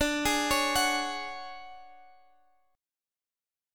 Listen to DM7b5 strummed